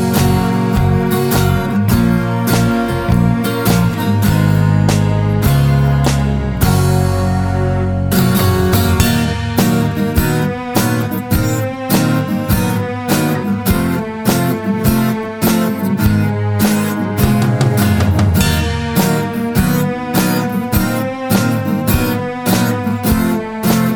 With Intro Pop (1960s) 3:27 Buy £1.50